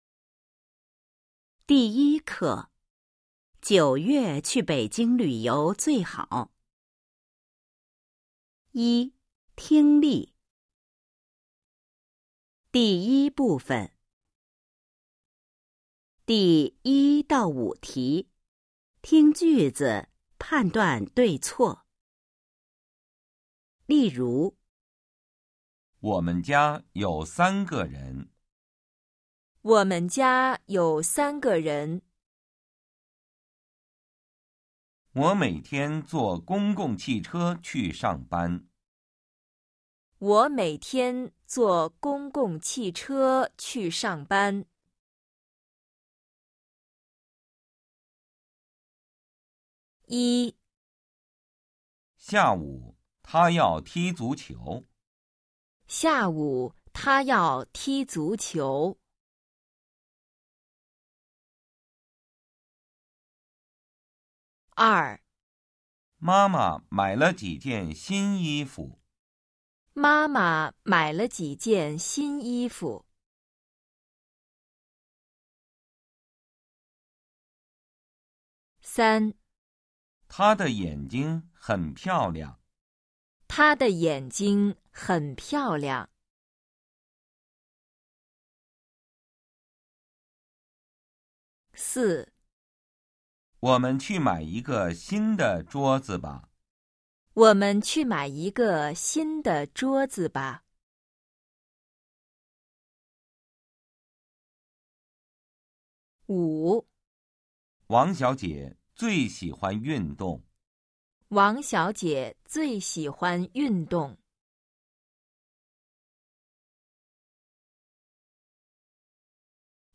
一、听力 Phần nghe 🎧 01-1